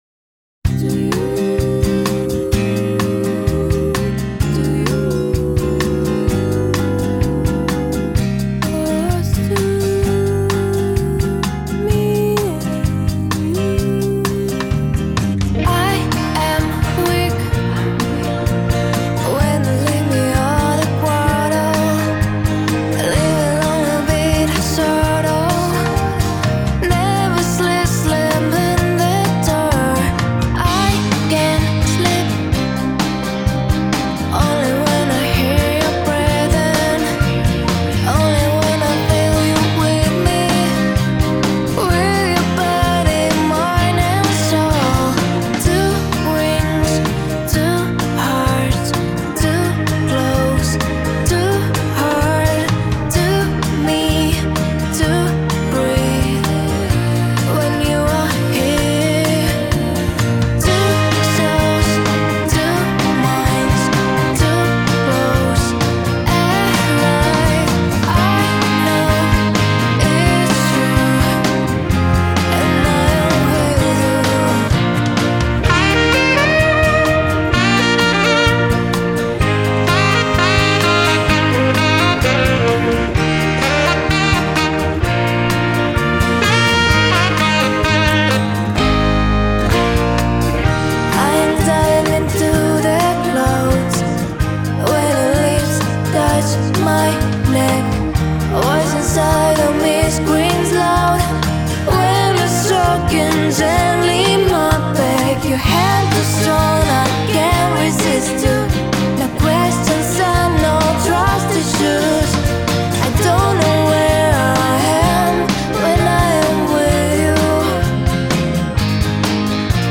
So wird deine Stimme heraus gestellt, die Instrumente haben ihren Stellenwert und das Saxophon dominiert so stellenweise eingesetzt auch richtig. Bei der Aufnahme fehlt mir halt der Zusammenhang, vielleicht liegt es an der Aufnahmetechnik, wo jeder seinen Part einzeln einspielt und die Band nicht miteinander swingt.
Mixtechnisch ist einiges im Argen, da müsst Ihr noch üben.